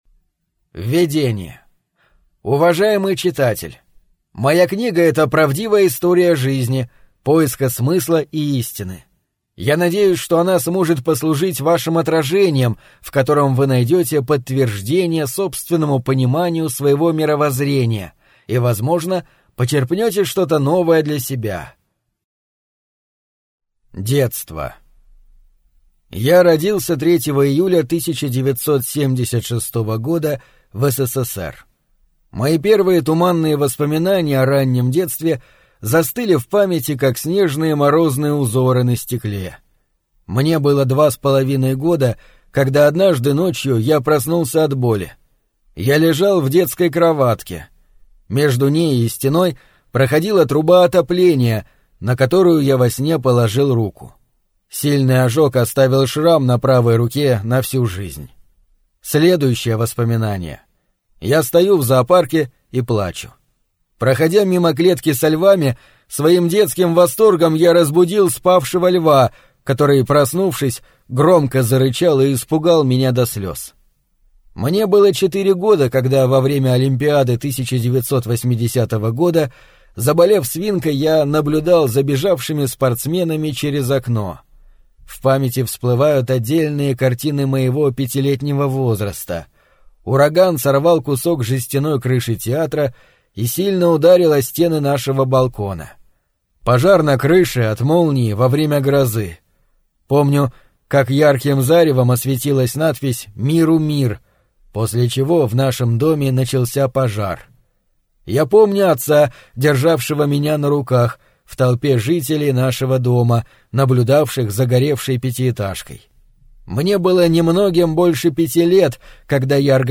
Аудиокнига Путь. Поиск Истины в Мире Лжи | Библиотека аудиокниг